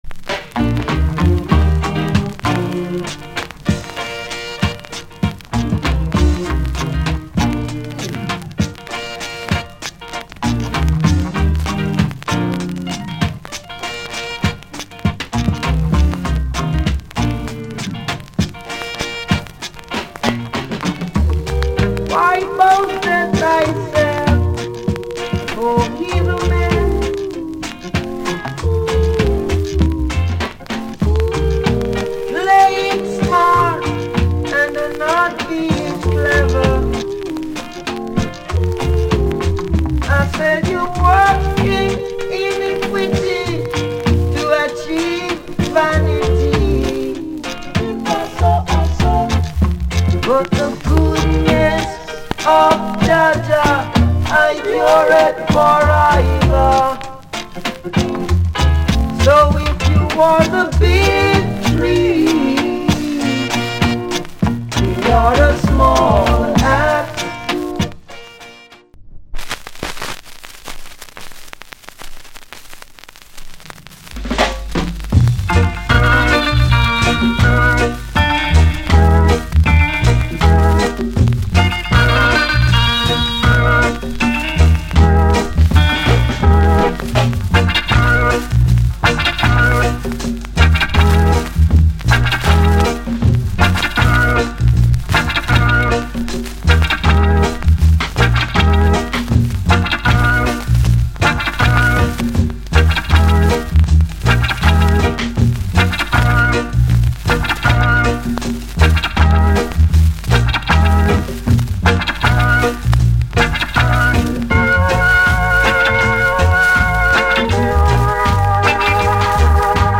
* Roots Gem / Nice Inst with Organ.